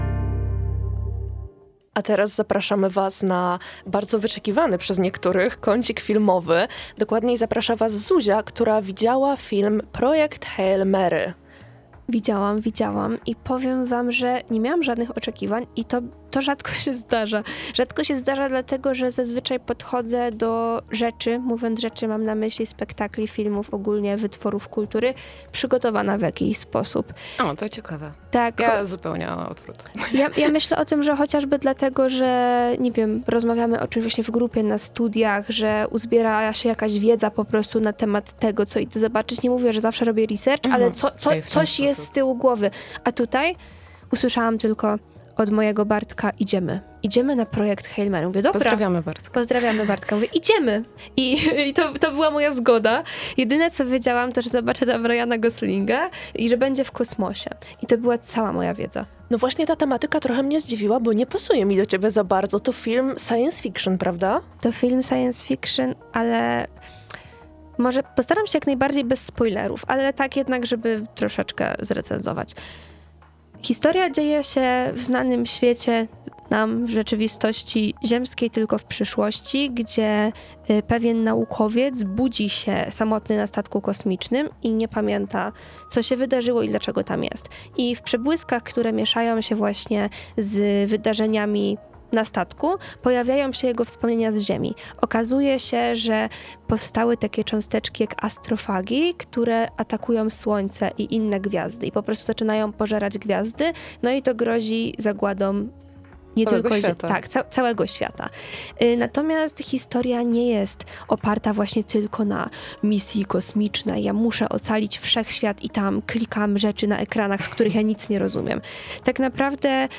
Co jeszcze można powiedzieć o tym zaskakującym filmie?